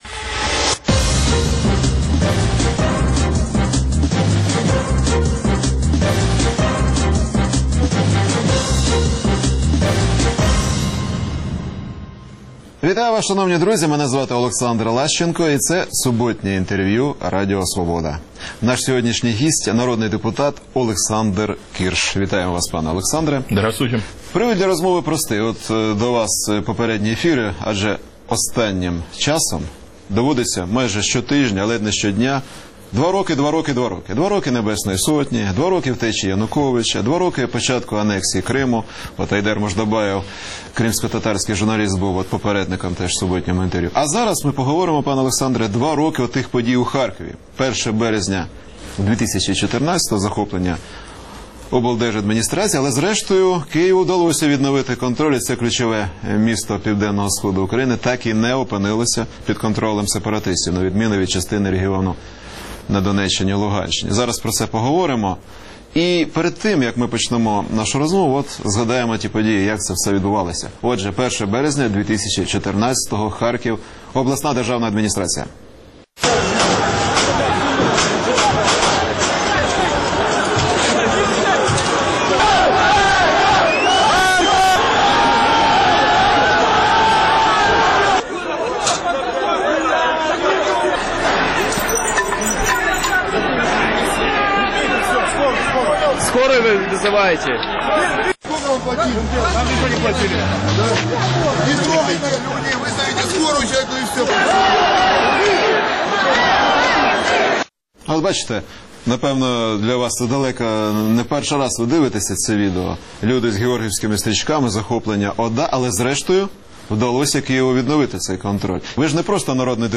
Суботнє інтерв’ю
Гість відповідає, в першу чергу, на запитання друзів Радіо Свобода у Фейсбуці